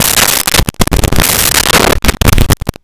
Door Heavy Open Close 03
Door Heavy Open Close 03.wav